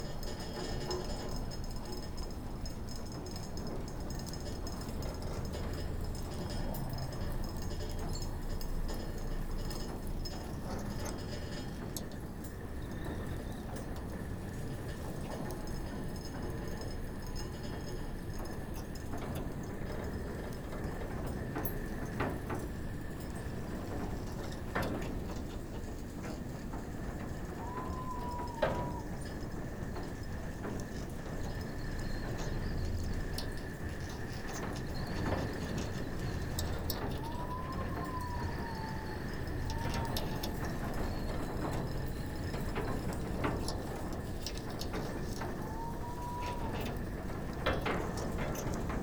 Enregistrements à la station de recherche
Sounds from the research station